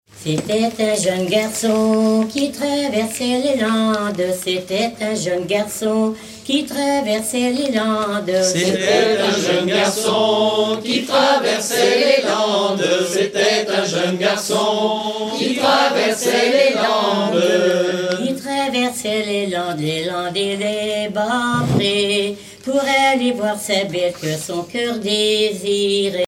Genre strophique
Chanteuse du pays de Redon
Pièce musicale éditée